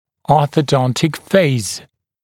[ˌɔːθə’dɔntɪk feɪz][ˌо:сэ’донтик фэйз]ортодонтический этап лечения